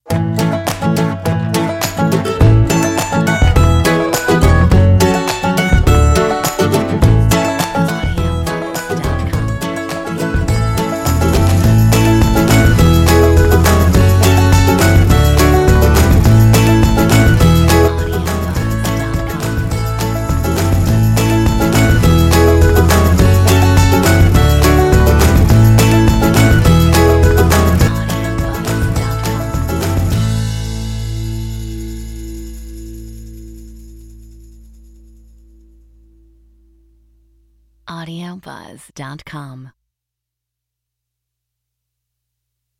Metronome 104